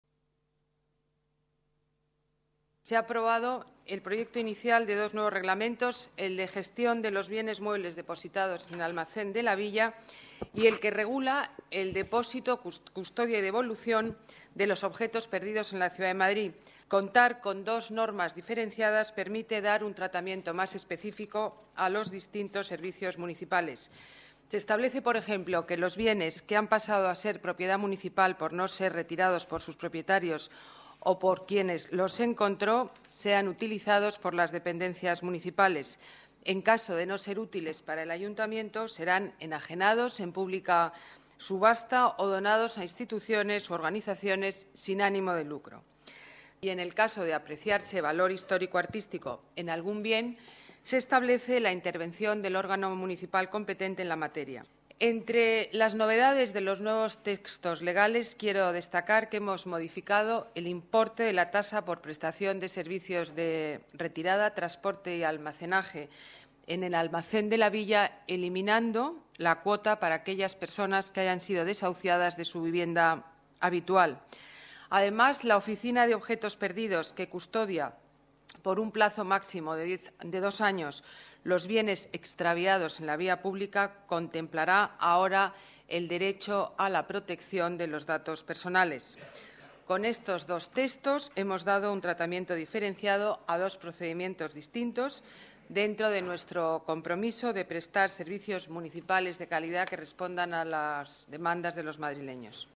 Nueva ventana:Declaraciones alcaldesa Madrid, Ana Botella: Junta de Gobierno, nuevos reglamentos Almacén de Villa y Objetos Perdidos